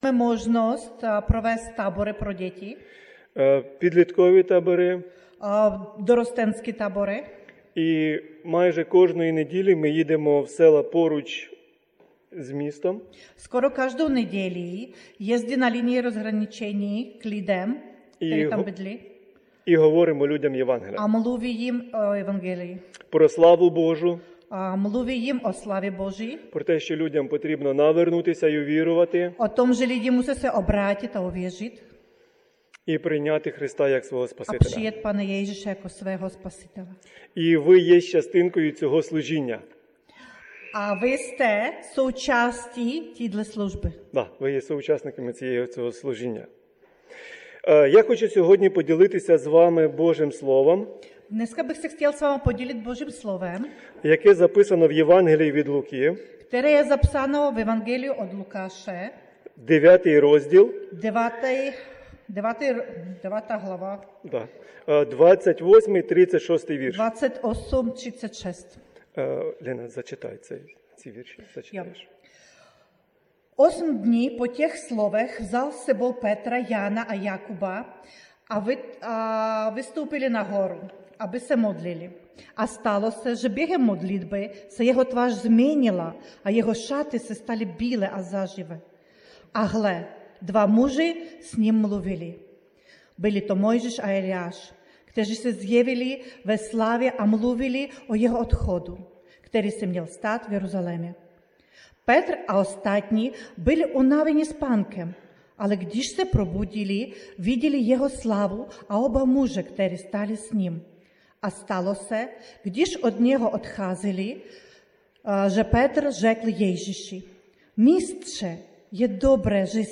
---více řečníků--- Kategorie: Nedělní bohoslužby Husinec přehrát